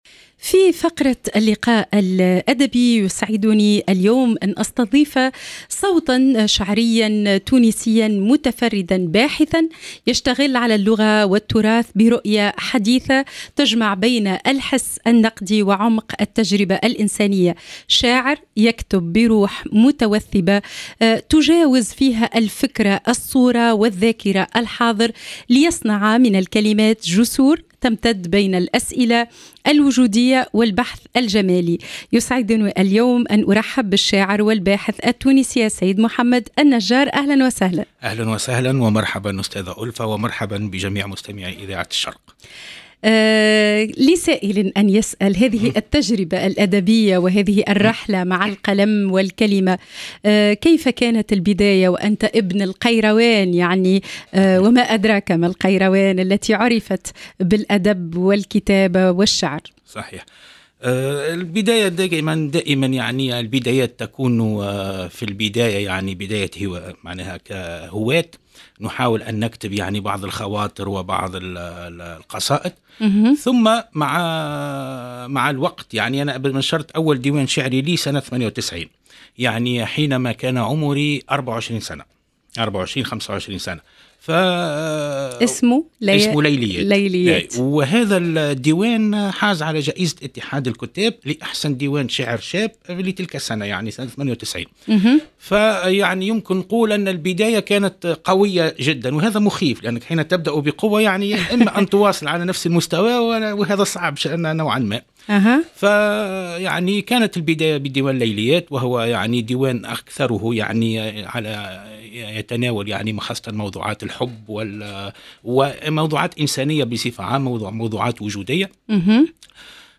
وقدم بعضا من نصوصه التي عكست حساسيته الفنية وعمق تجربته.